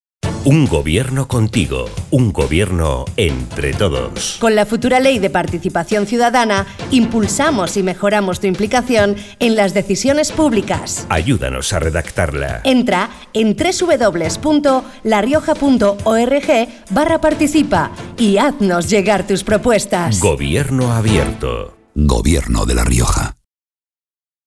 Elementos de campaña Cuñas radiofónicas Cuña genérica.